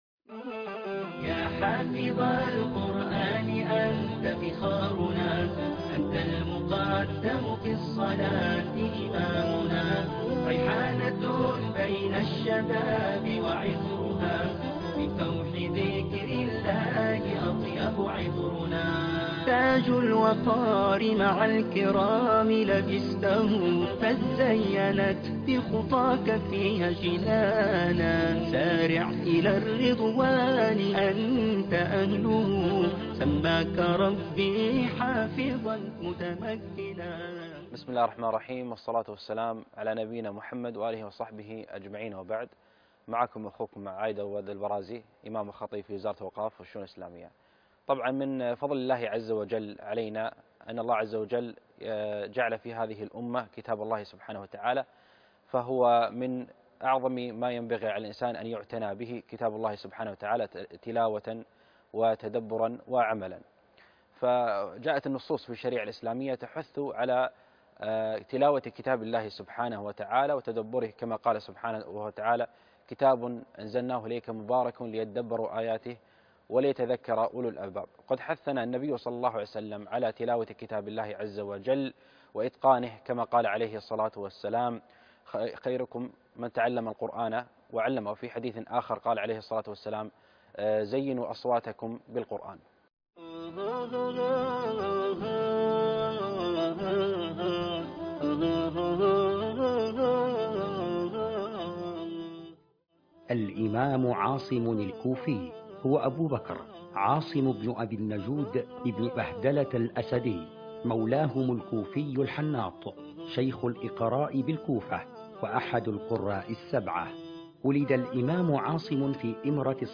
القاريء